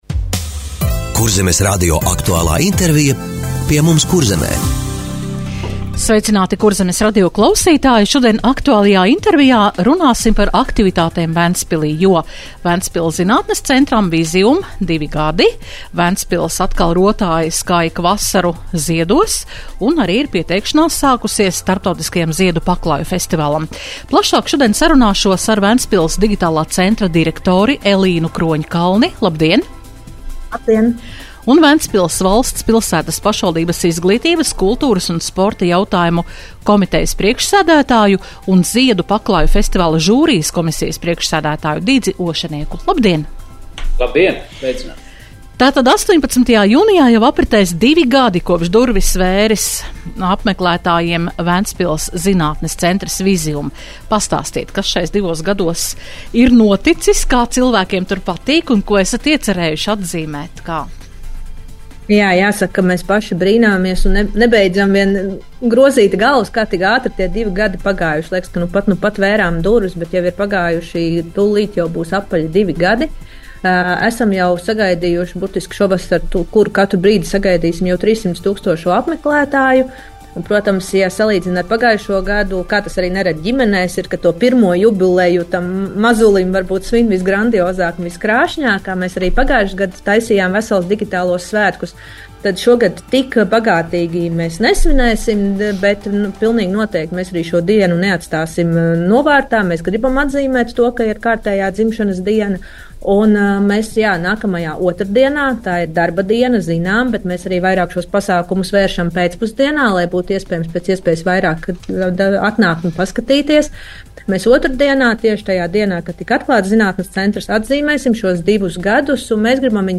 Radio saruna Ventspils pilsētas aktualitātes - Ventspils